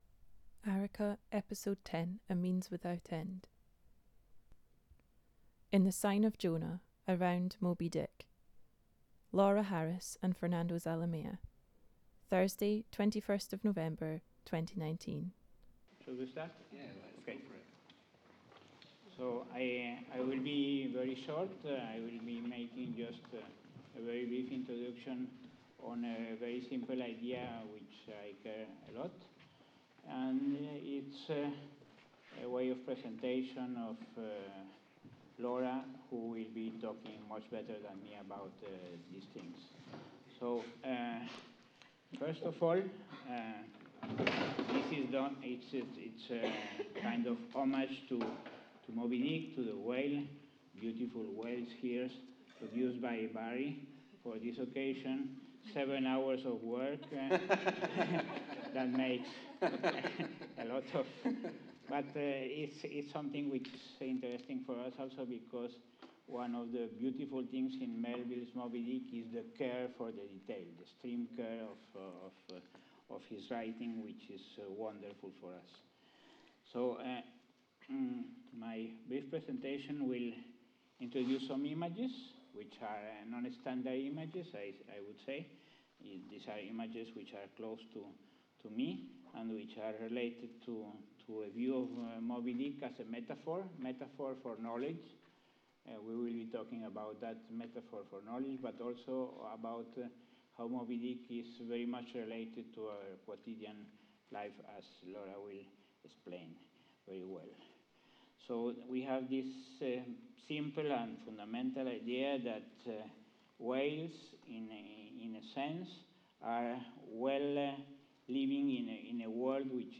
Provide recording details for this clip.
Venue Tramway, Glasgow